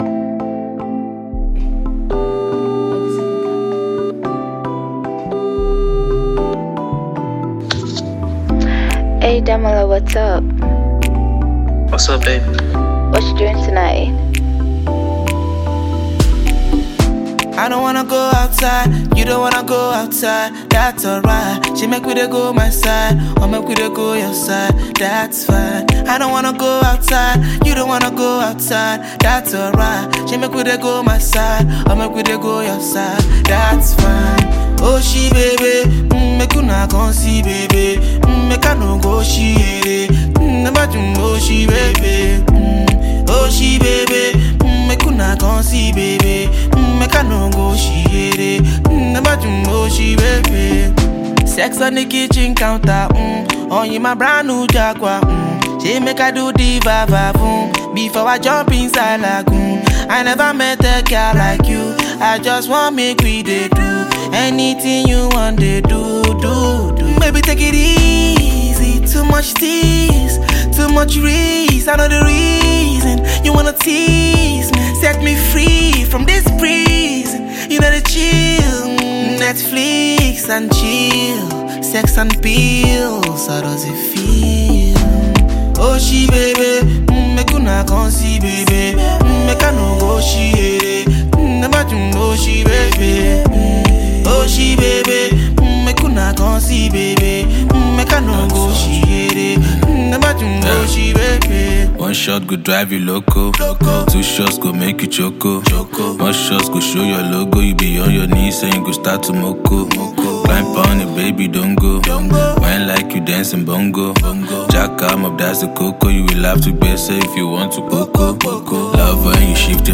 seductive song